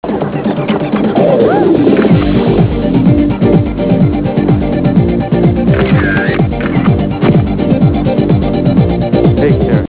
Comment: electronica